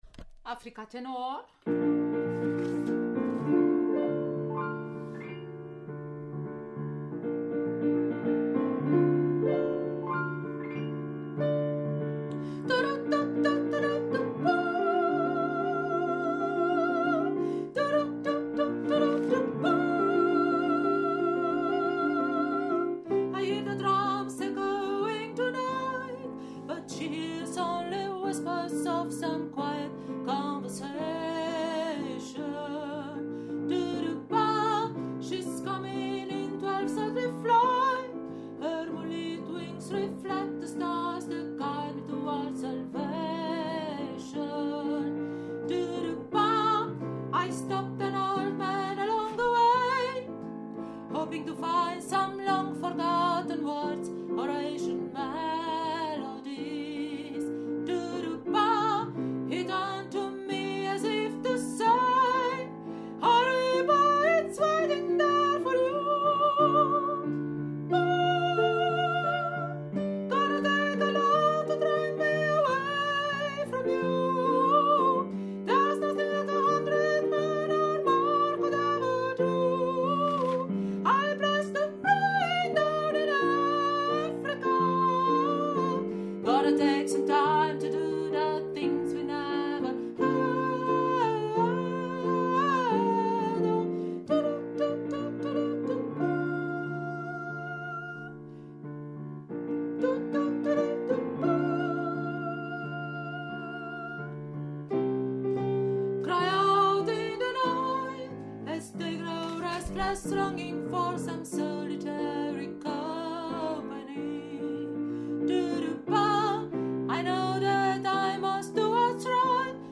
Africa – Tenor
Africa-Tenor.mp3